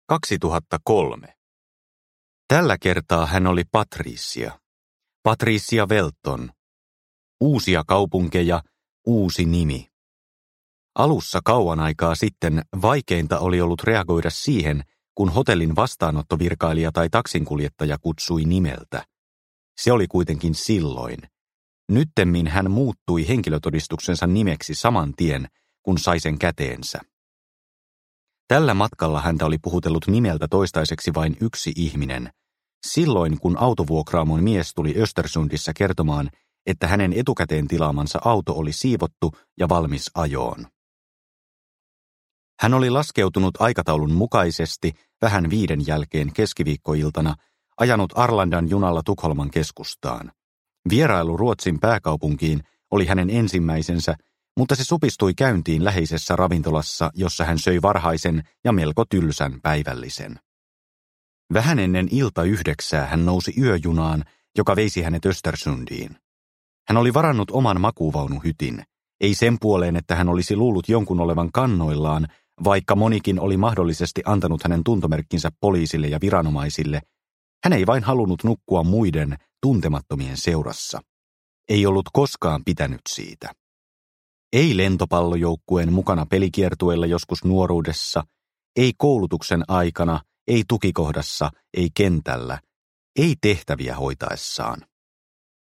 Tunturihauta – Ljudbok – Laddas ner